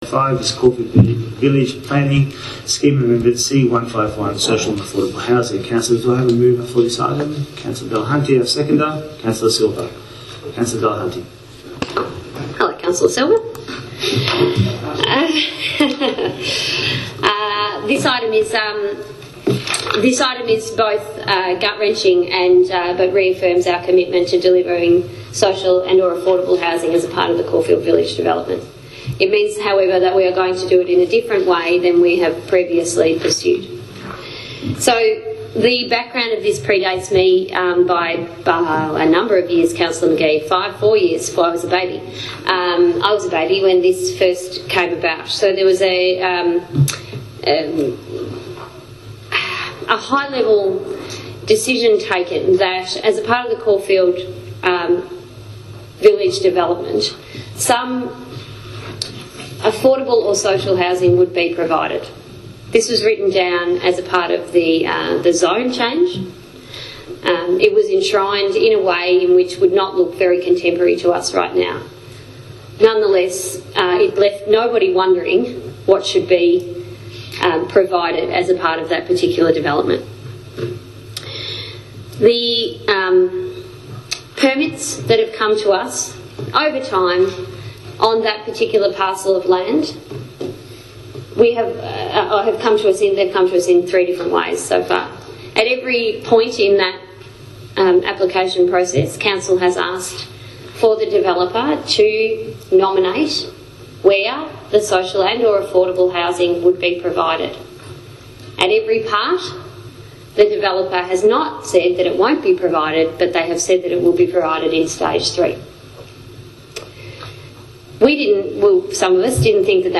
What is staggering about the ‘debate’ is: Every single councillor carefully avoided use of the word ‘abandon’ Residents would be hard pressed to decipher council’s position on the officer recommendations, especially when Delahunty made such repeated comments as ‘we will not give up’ plus labelling the MRC as ‘disgusting’.